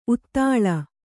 ♪ uttāḷa